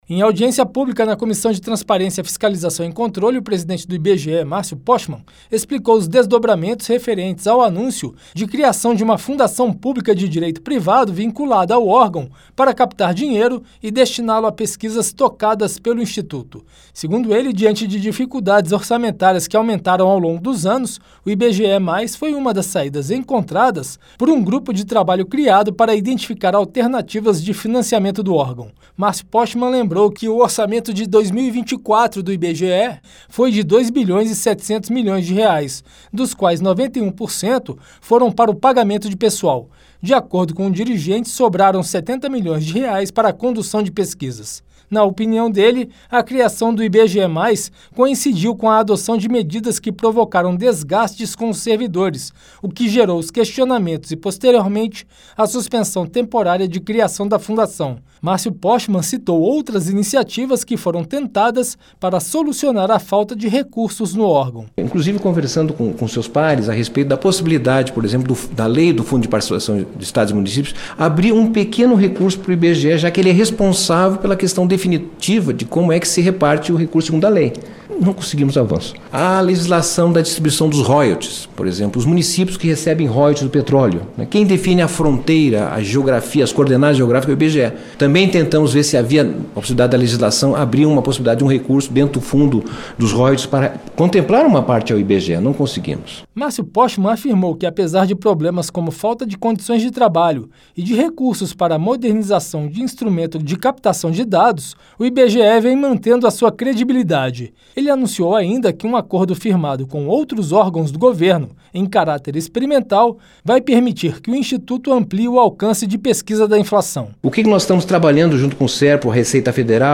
Em audiência pública nesta quarta-feira (22) na Comissão de Transparência, Fiscalização e Controle, o presidente do IBGE, Marcio Pochmann, afirmou que restrição orçamentária motivou a criação de uma fundação pública de direito privado vinculada ao instituto para captar dinheiro e destiná-lo a pesquisas tocadas pelo órgão. Por causa de repercussão negativa gerada por resistência de funcionários do órgão, a criação do IBGE+ foi suspensa pelo Ministério do Planejamento.